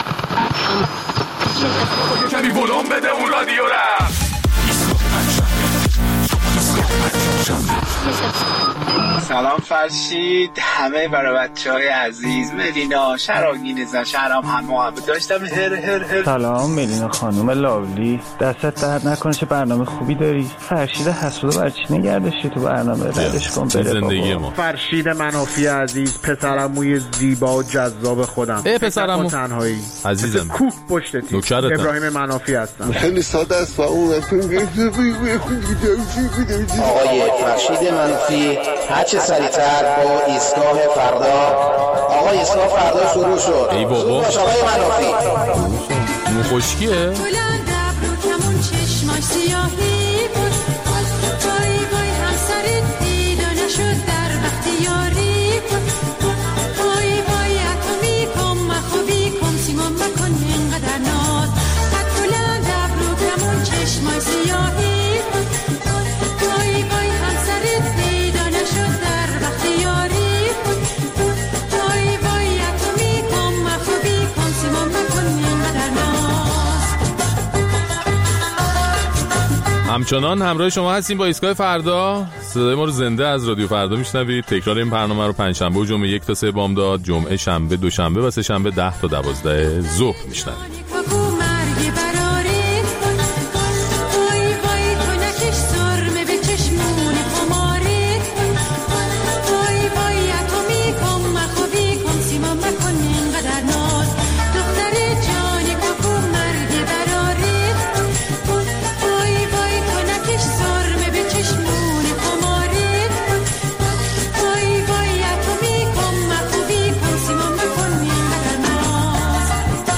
در این برنامه ادامه نظرات شما را در مورد رکورد جدید قیمت دلار و نتایج آن بر زندگی مردم می‌شنویم. در ایستگاه‌ بین‌الملل هم از جمله به درگیری‌های اخیر بین ایران و اسرائیل می‌پردازیم.